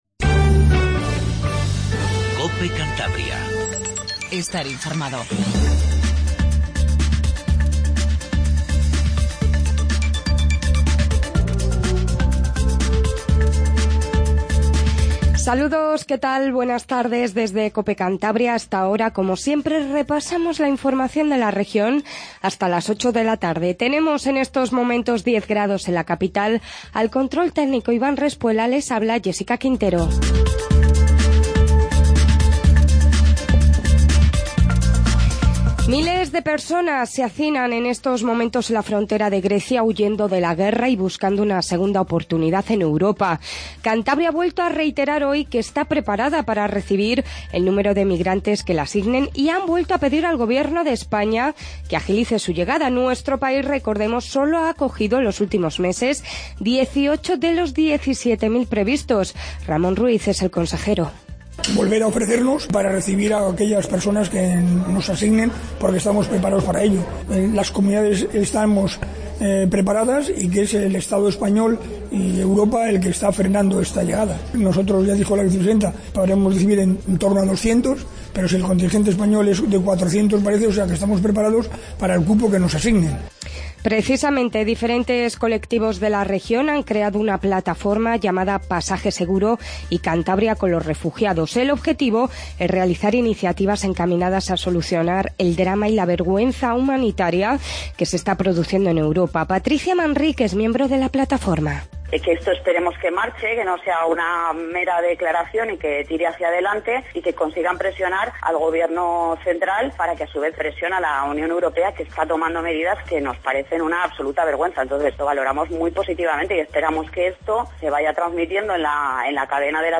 INFORMATIVO TARDE 19:50